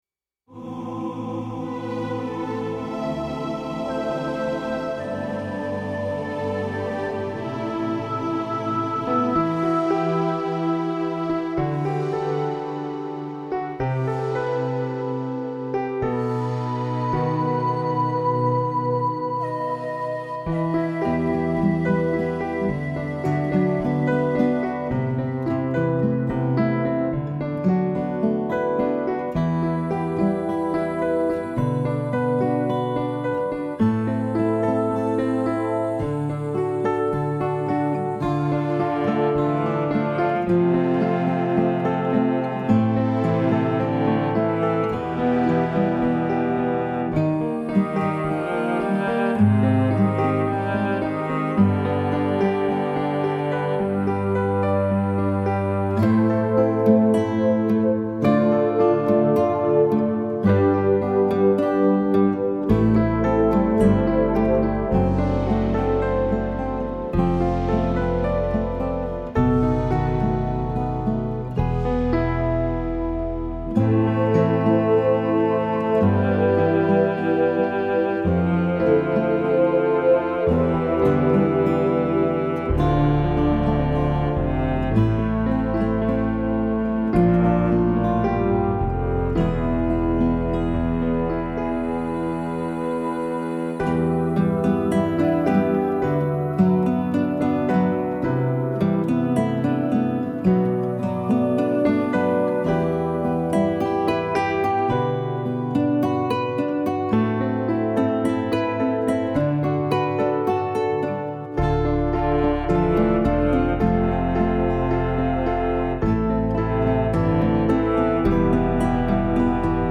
I want to share my newest song with an instrumental/karaoke version: